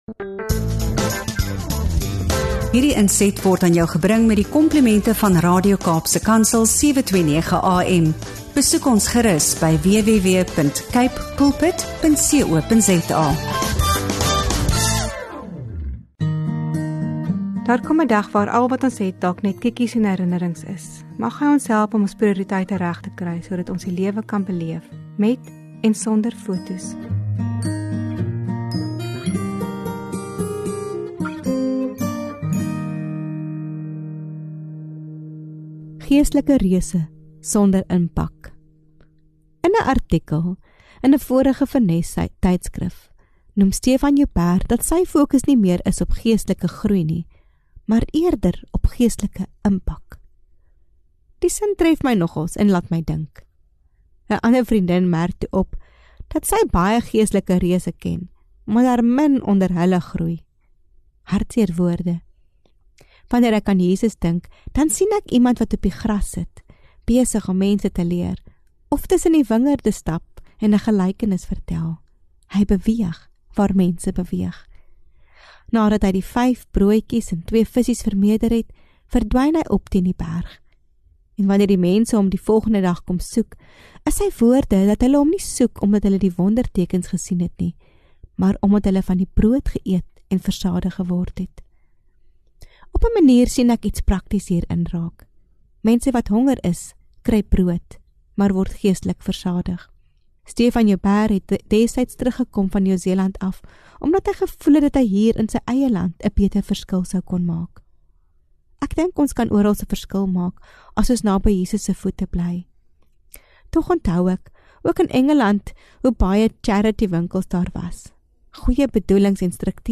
In hierdie aangrypende gesprek